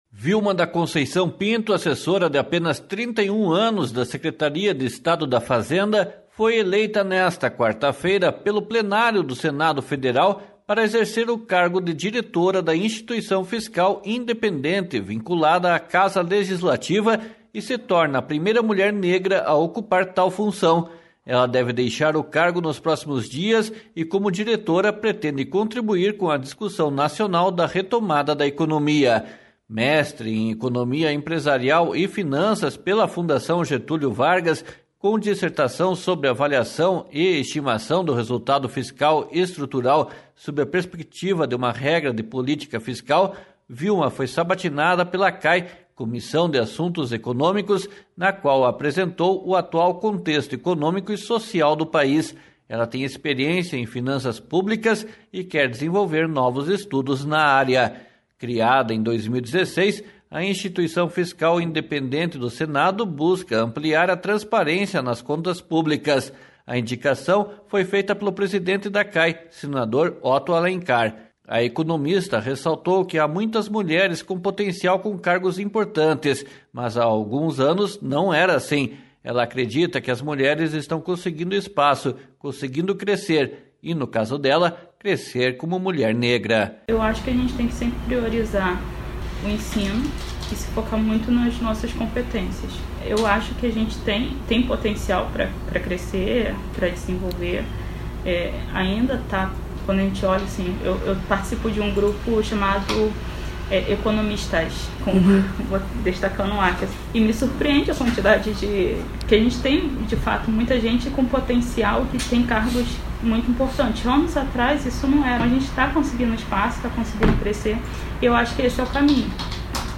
//SONORA VILMA DA CONCEIÇÃO PINTO//